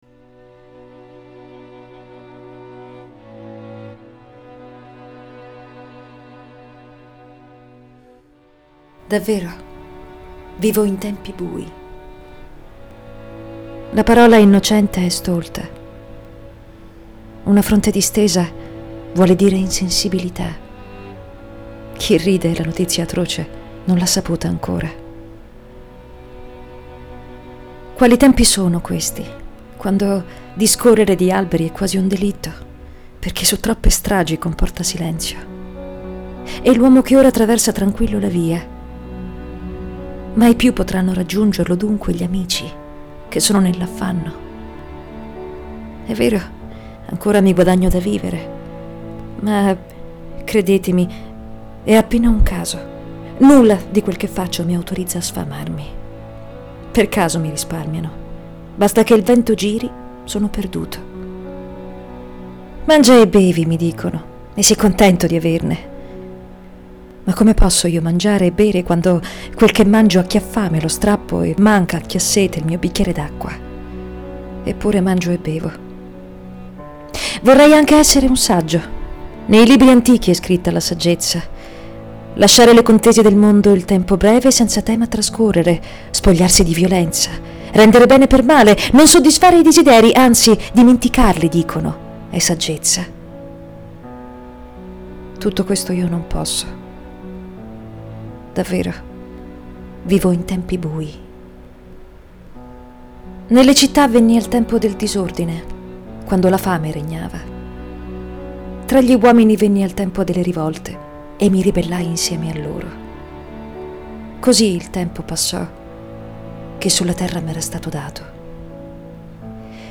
Suoni poetici » Poesie recitate da artisti
attrice e doppiatrice